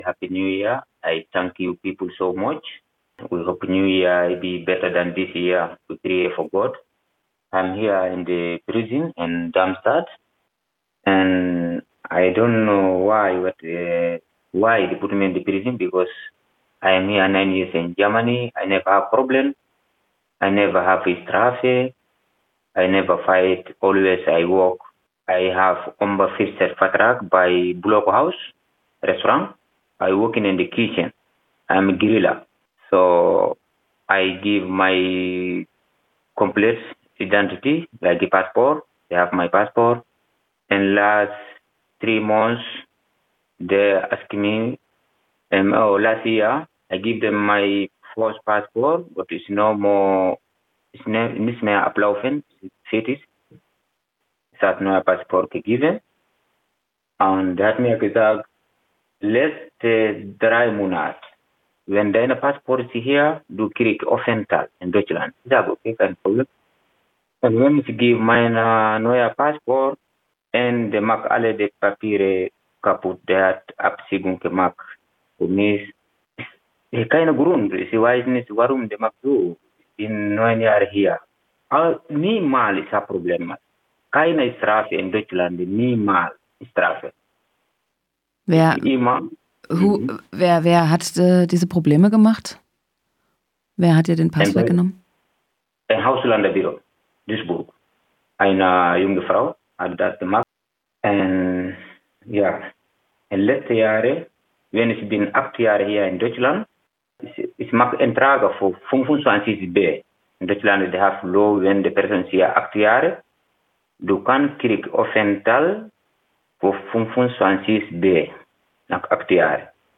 Wie jedes Jahr wurde ein Teil der Kundgebung auf Radio Dreyeckland live übertragen, damit jene, die nicht frei daran teilnehmen können, in ihren Zellen über ihre Radios die Reden verfolgen konnten. Leider war aufgrund technischer Schwierigkeiten die Qualität anfangs - sagen wir mal - mies, weshalb manche der von uns aufgenommenen Redebeiträge etwas schlecht verständlich sind.